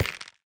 Minecraft Version Minecraft Version 1.21.5 Latest Release | Latest Snapshot 1.21.5 / assets / minecraft / sounds / block / nether_wood_hanging_sign / step3.ogg Compare With Compare With Latest Release | Latest Snapshot
step3.ogg